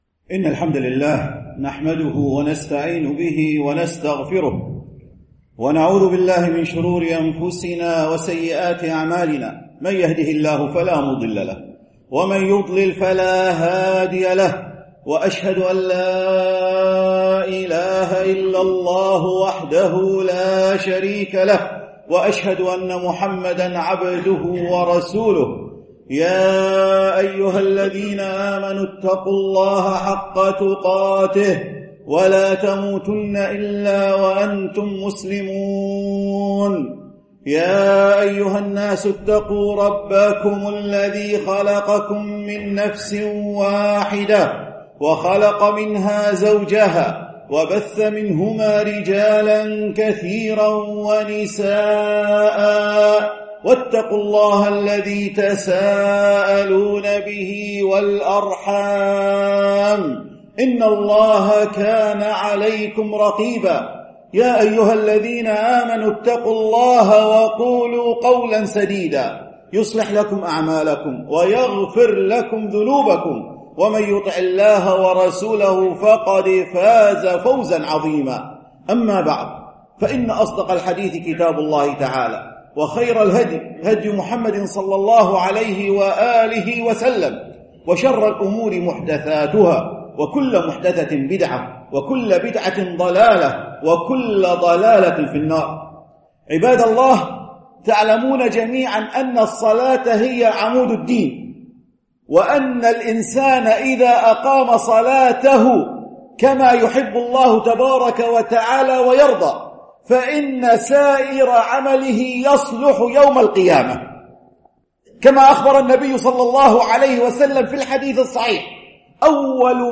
خطبة الجمعة بتاريخ 24 يناير ، 2014